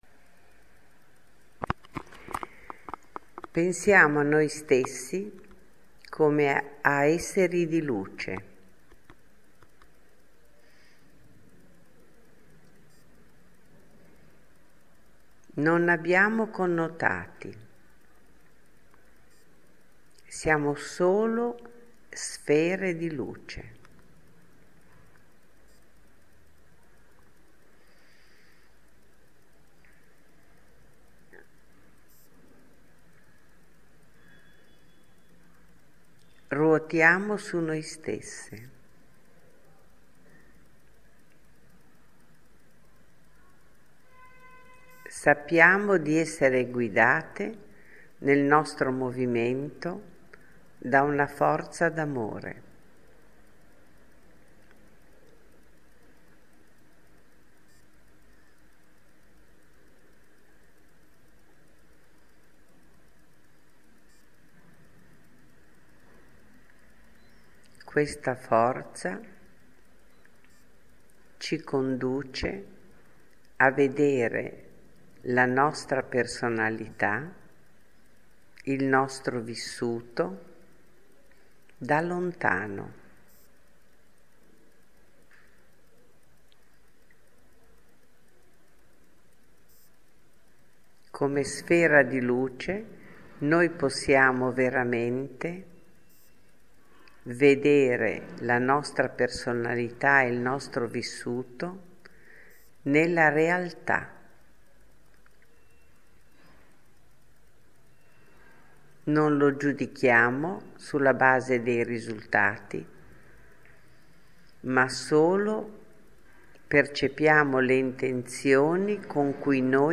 Esseri di Luce – meditazione
esseri-di-Luce-meditazione.mp3